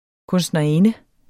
Udtale [ kɔnsdnʌˈenə ]